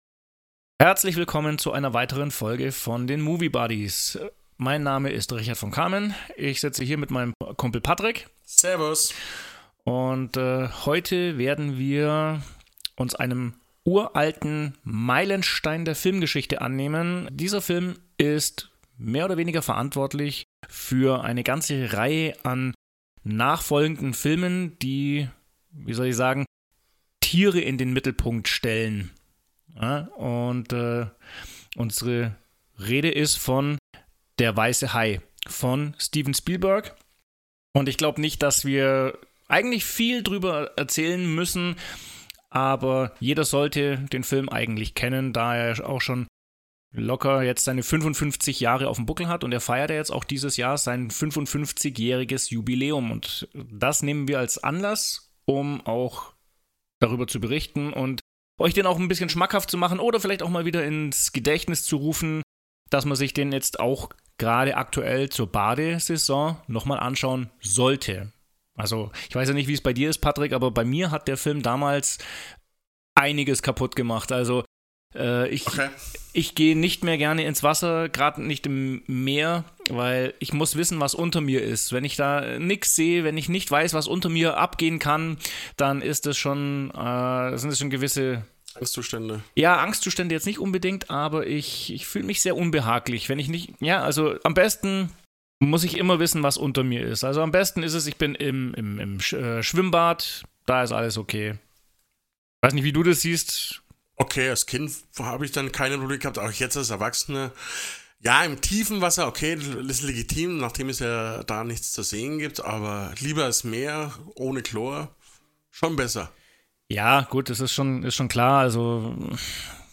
Steven Spielbergs Meilenstein inspiriert die beiden Podcaster so sehr, dass es zu der bis Dato längsten und abwechslungsreichsten Folge führt und zu zahlreichen "Nebenthemen". Freut euch auf einen sympathischen Gedankenaustausch, zu dem ihr alle in den Spotify-Kommentaren eingeladen seid.